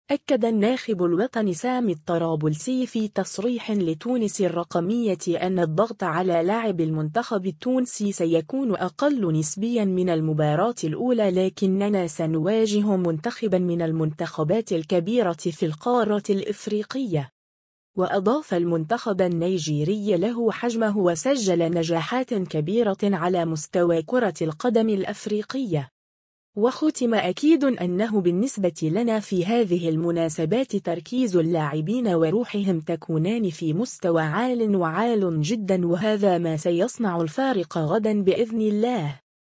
أكّد الناخب الوطني سامي الطرابلسي في تصريح لتونس الرقمية أنّ الضغط على لاعبي المنتخب التونسي سيكون أقل نسبيا من المباراة الأولى لكننا سنواجه منتخبا من المنتخبات الكبيرة في القارة الإفريقية.